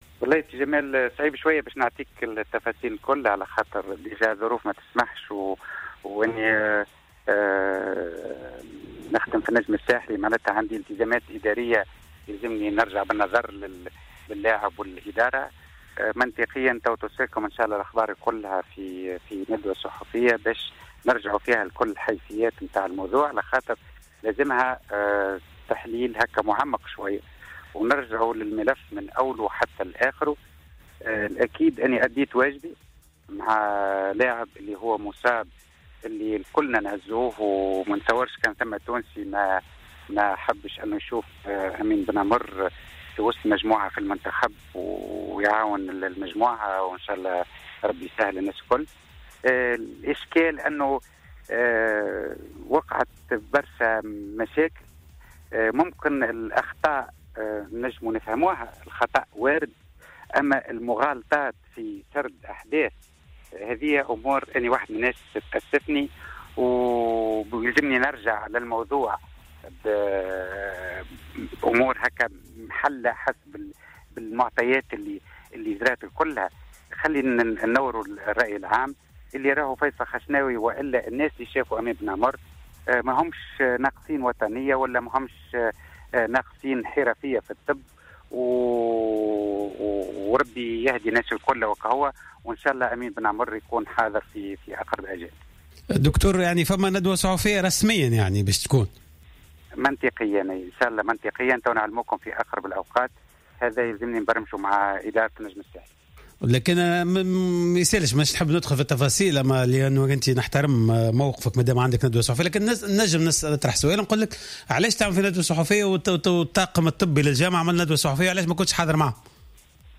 خلال مداخلته في برنامج 'قوول'